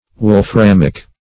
wolframic - definition of wolframic - synonyms, pronunciation, spelling from Free Dictionary Search Result for " wolframic" : The Collaborative International Dictionary of English v.0.48: Wolframic \Wol*fram"ic\, a. (Chem.)